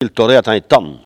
Mots Clé parole, oralité
Enquête Arexcpo en Vendée
Catégorie Locution